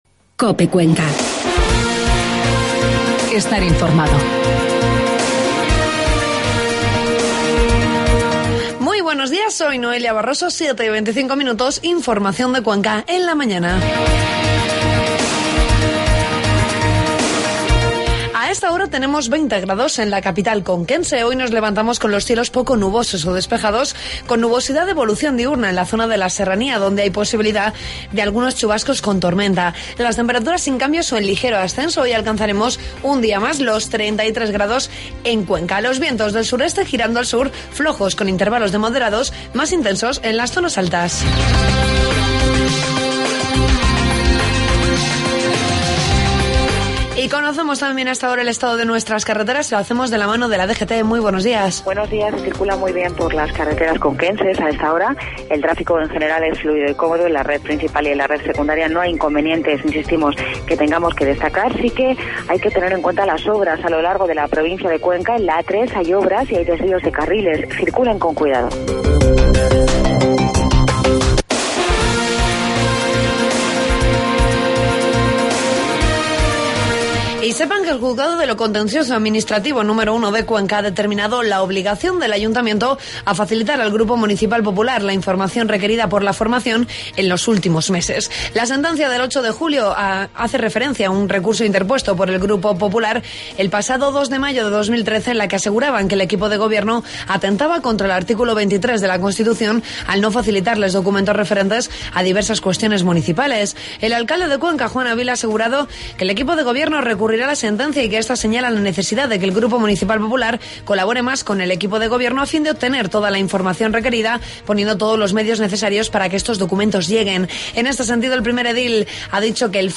Informativo matinal 16 de julio